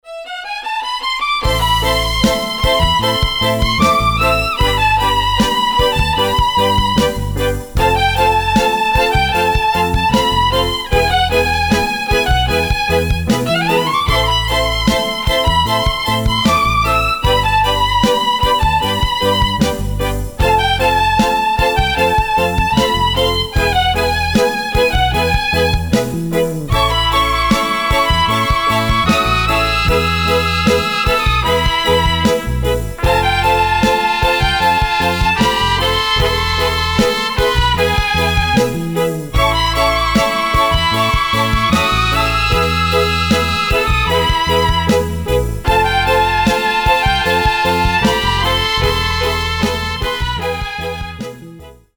Рингтоны без слов
Скрипка
Инструментальные